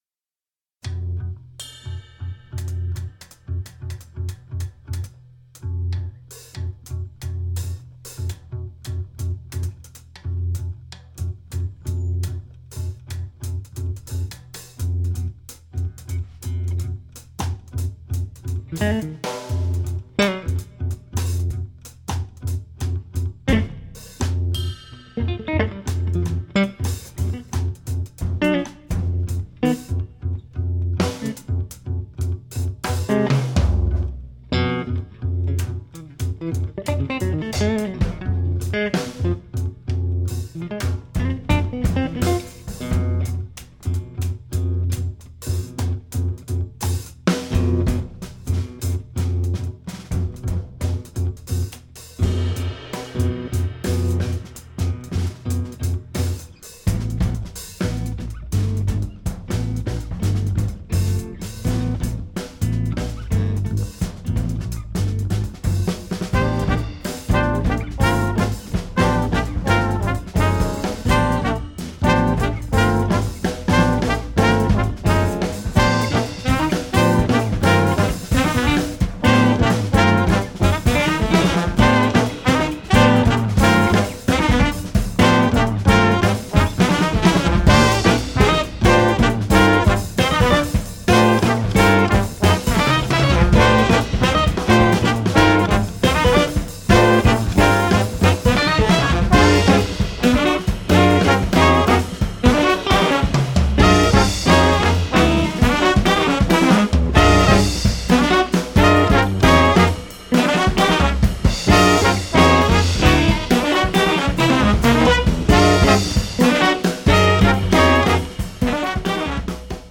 electric guitar
double bass
drums
trumpet
saxophones
trombone
Recorded live at Le Triton, Les Lilas, France